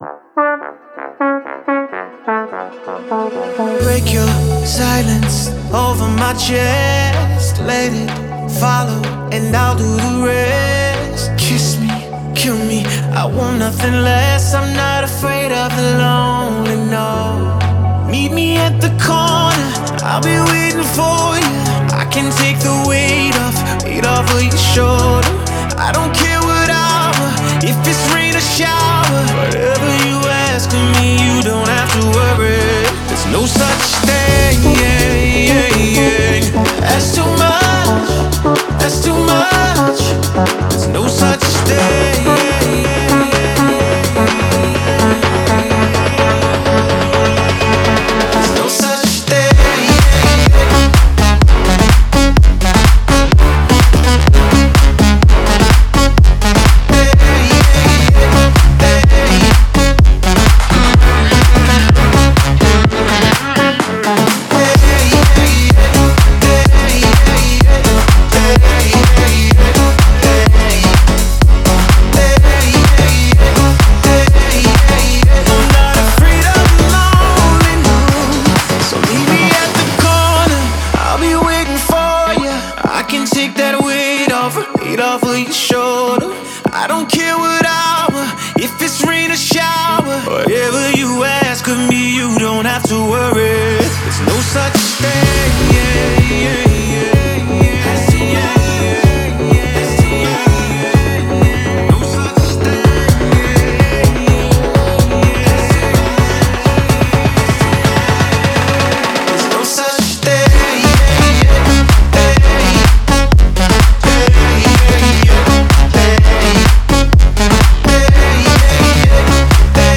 добавляет яркие мелодические элементы и танцевальные биты